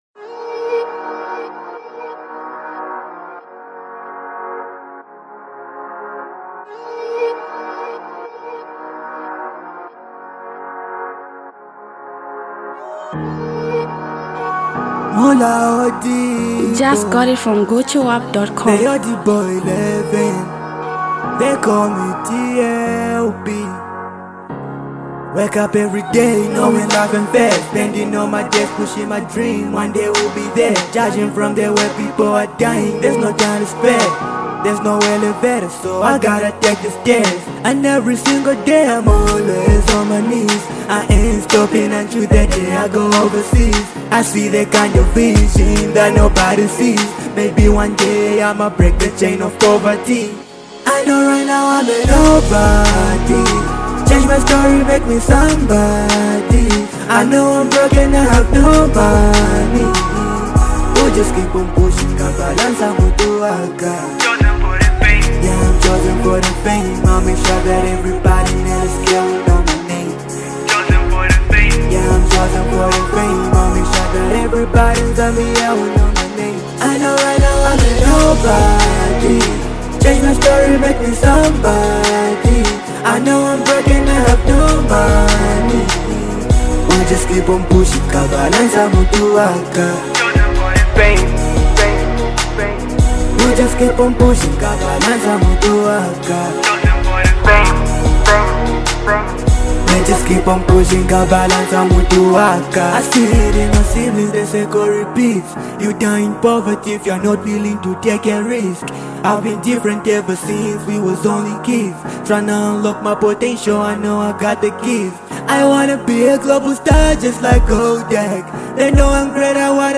singer, songwriter and rapper
powerful melodic sound